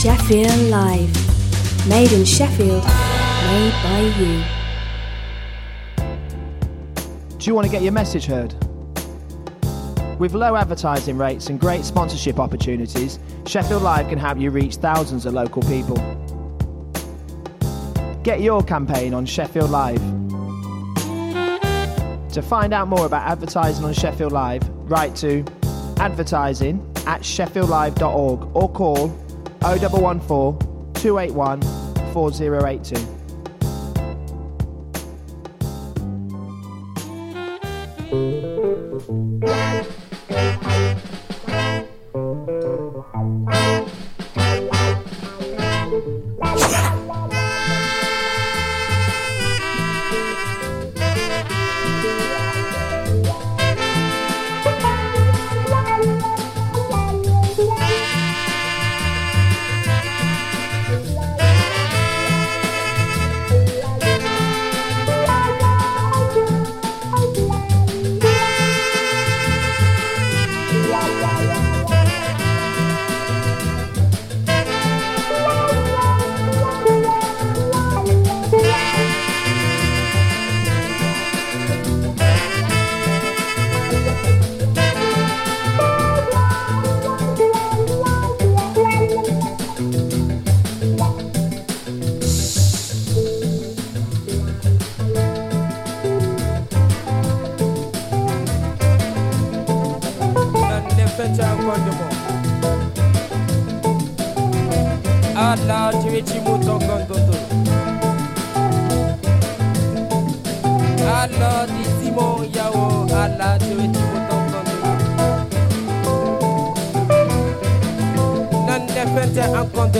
Playing the very best in urban music, new & old…